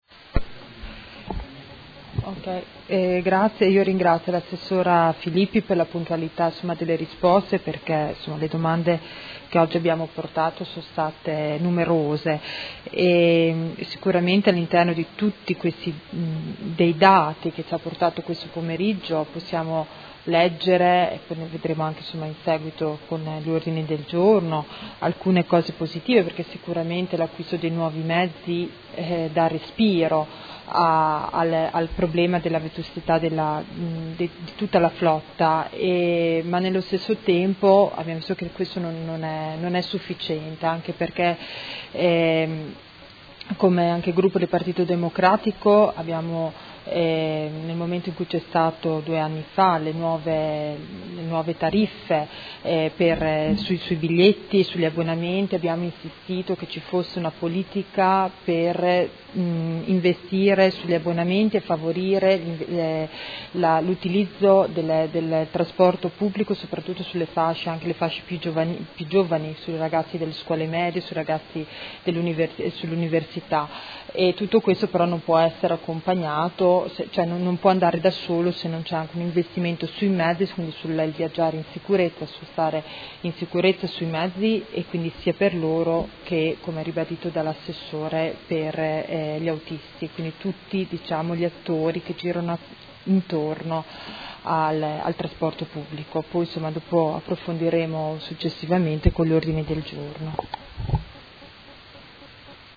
Seduta del 21/06/2018 Replica a risposta Assessora. Interrogazione del Consigliere Carpentieri (PD) avente per oggetto: TPL a Modena